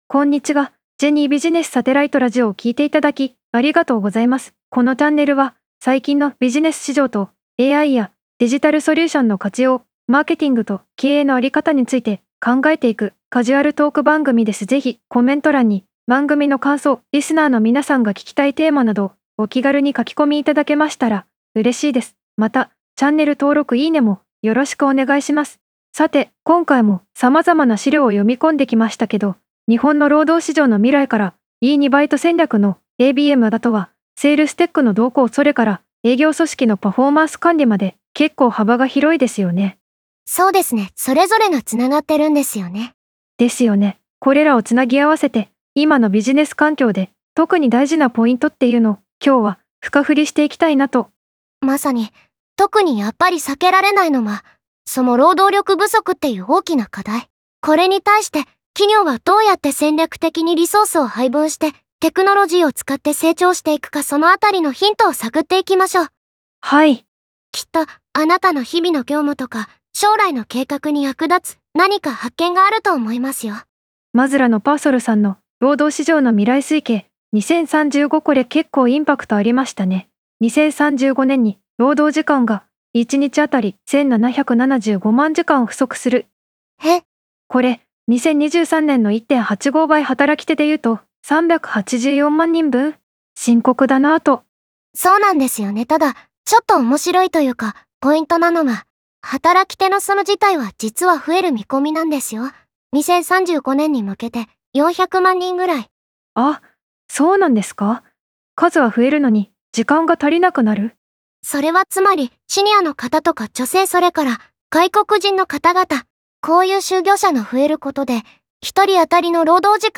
AivisSpeechでの合成結果
• AivisSpeechは、特に専門用語（例: SaaS→サーズ、労働力不足→ろうどうりょくぶそく、Terra Sky→テラスカイ）をデフォルトで自然に読む